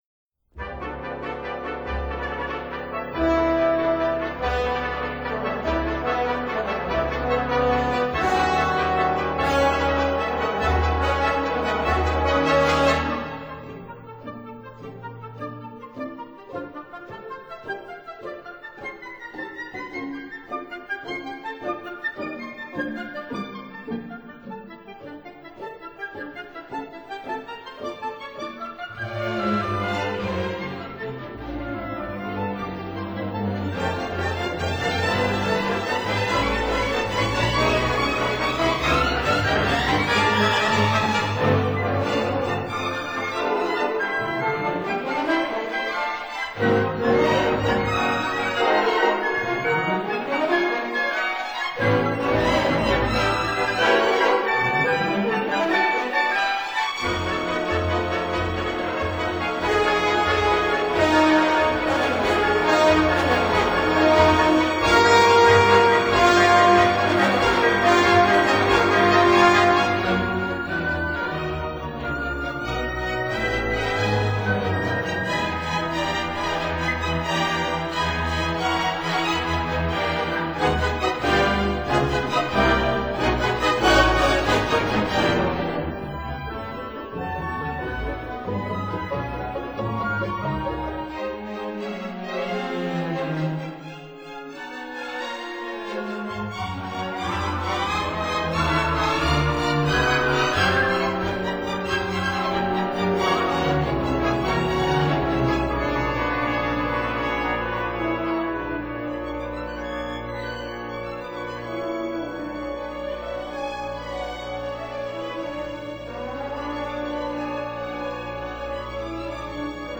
他的音樂風格較為保守，有一定印象主義特徵，擅長配器。